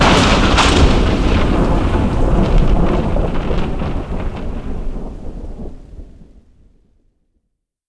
Thunder05.wav